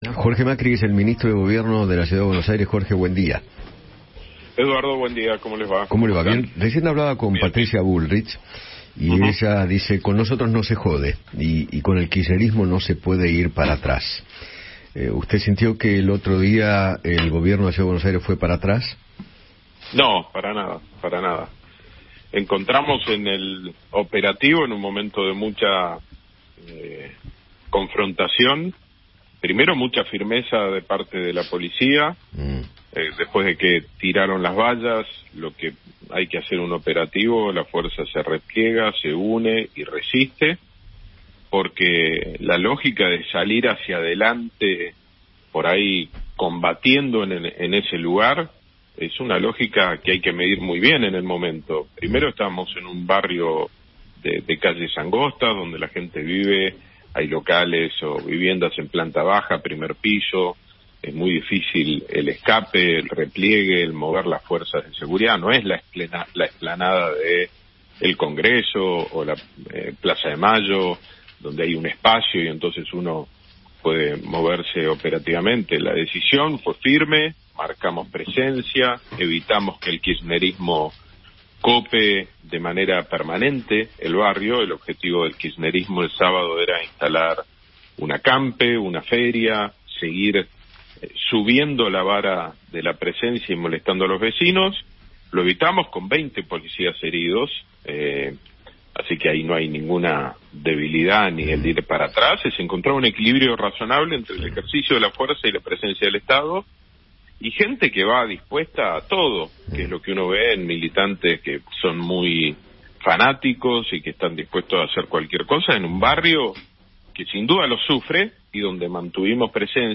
Jorge Macri, ministro de Gobierno de la Ciudad de Buenos Aires, conversó con Eduardo Feinmann sobre el accionar de la policía durante la manifestación en apoyo a Cristina Kirchner y contó cómo continuará actuando el Gobierno porteño.